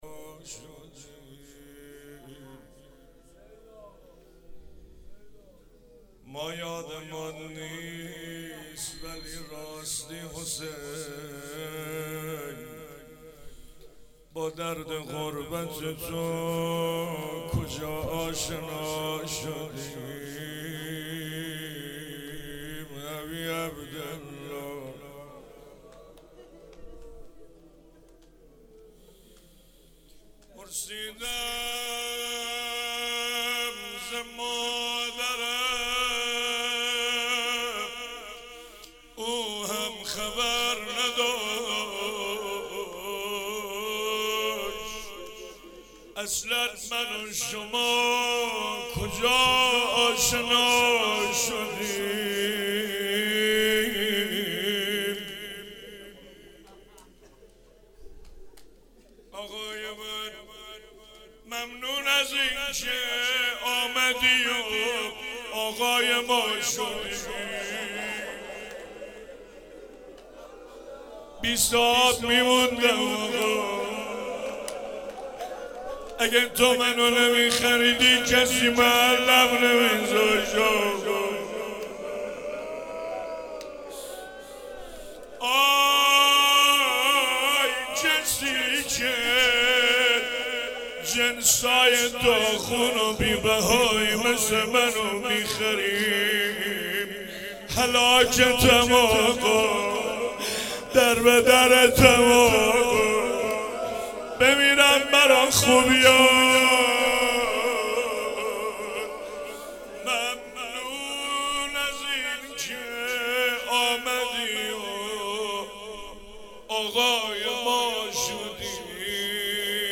شب سوم رمضان 95، حاح محمدرضا طاهری
01 heiat alamdar mashhad.mp3